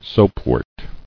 [soap·wort]